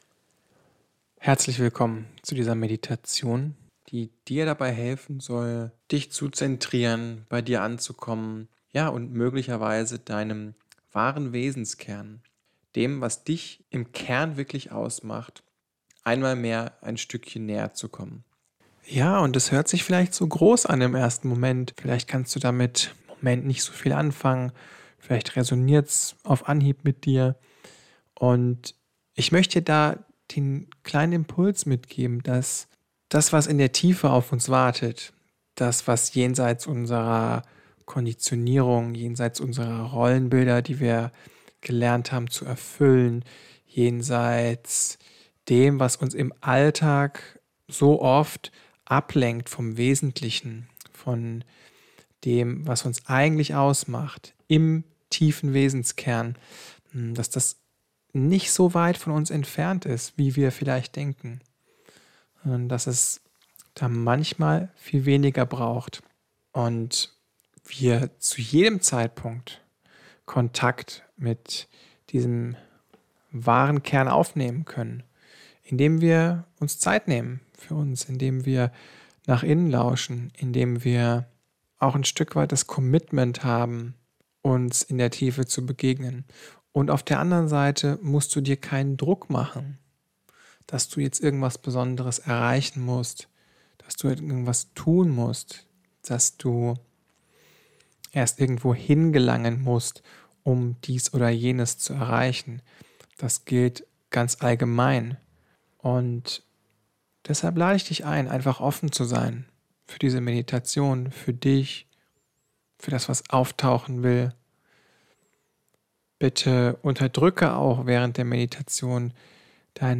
Eine 15-minütige True Self Meditation✨